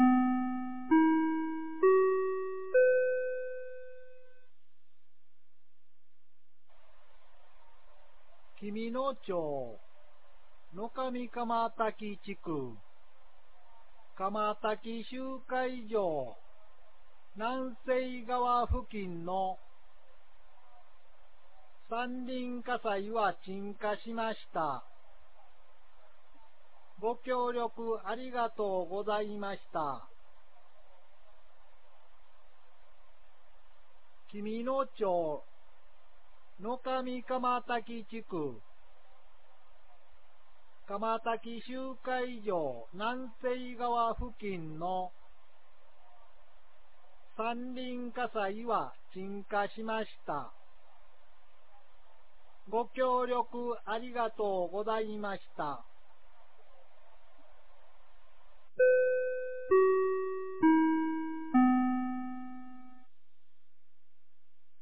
2022年05月11日 15時47分に、紀美野町より全地区へ放送がありました。